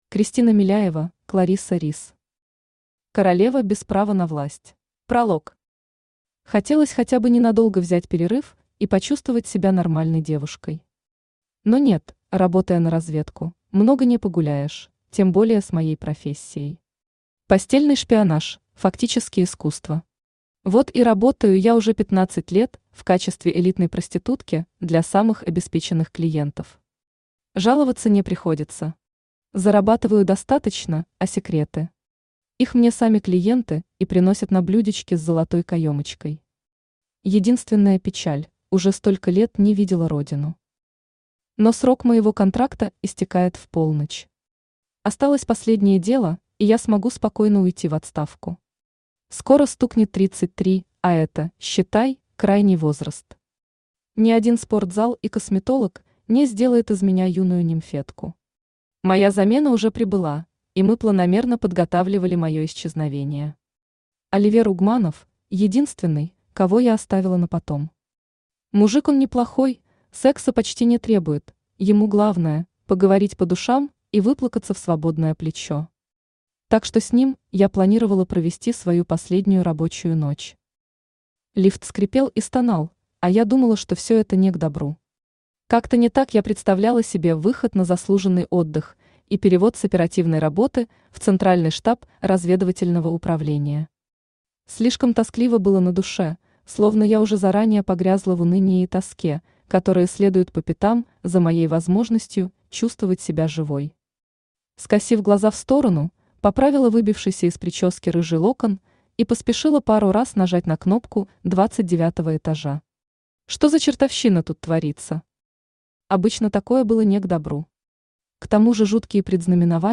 Аудиокнига Королева без права на власть | Библиотека аудиокниг
Aудиокнига Королева без права на власть Автор Кларисса Рис Читает аудиокнигу Авточтец ЛитРес.